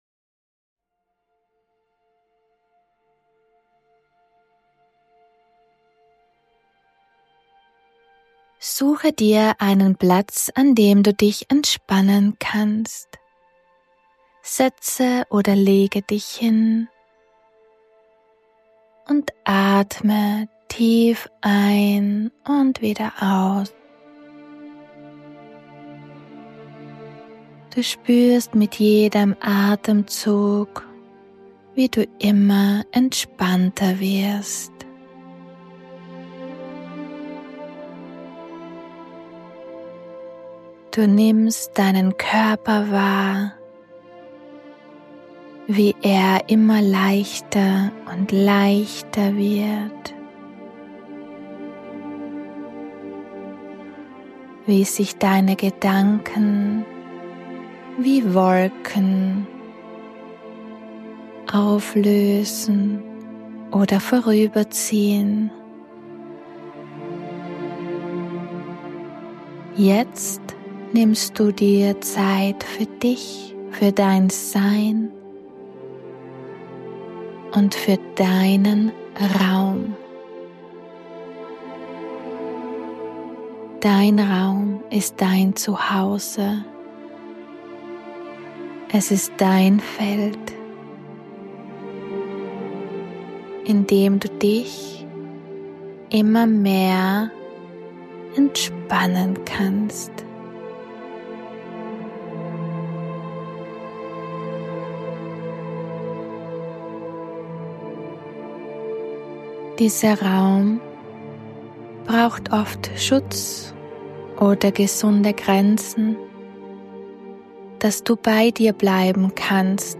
071 I Affirmationsmeditation: Königinnen-Energie ~ Intu Soul - Der Podcast